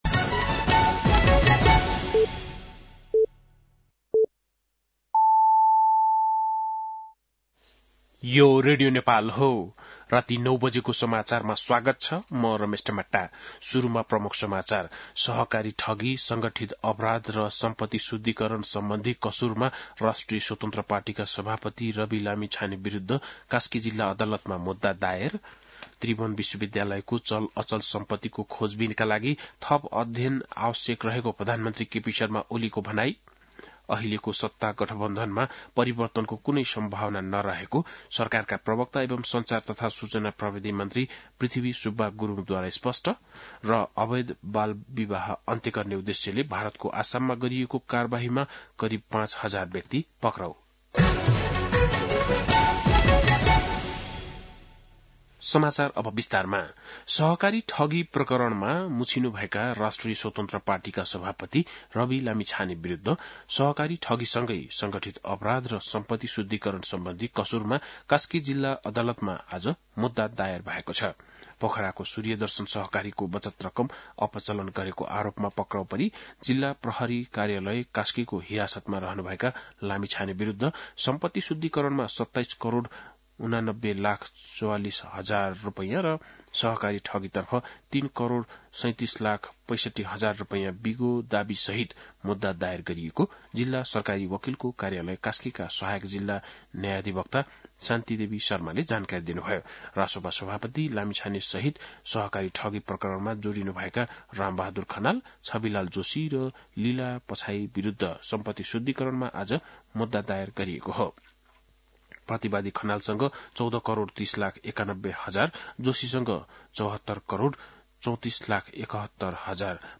बेलुकी ९ बजेको नेपाली समाचार : ८ पुष , २०८१
9-PM-Nepali-News-9-7.mp3